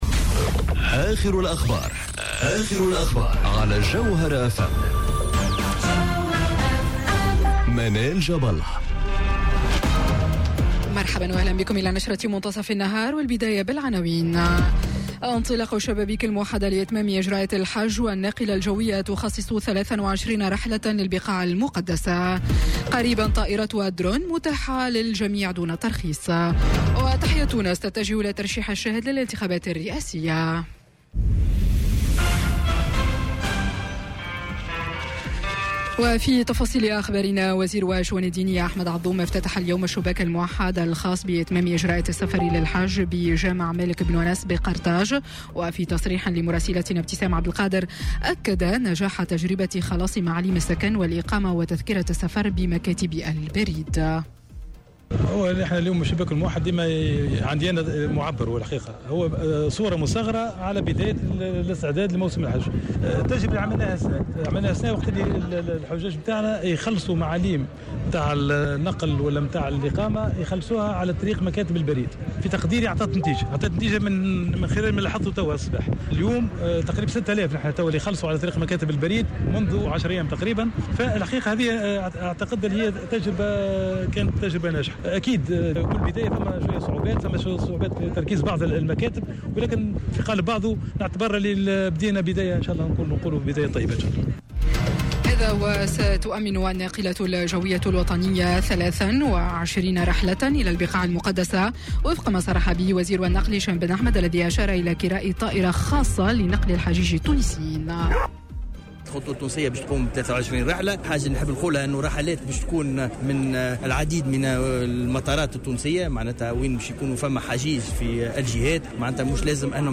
نشرة أخبار منتصف النهار ليوم الإثنين 10 جوان 2019